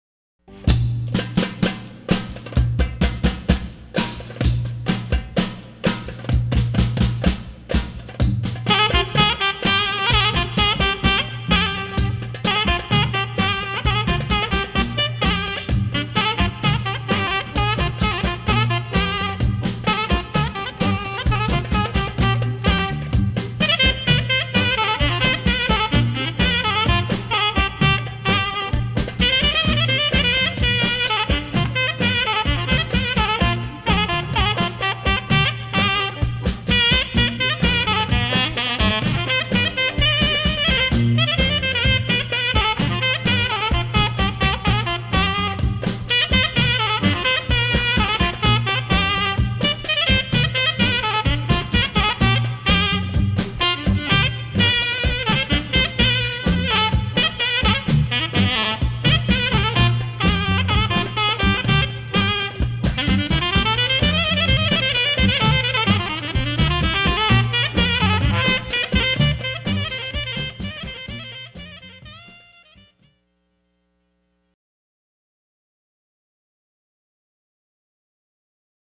Makedonska Gaida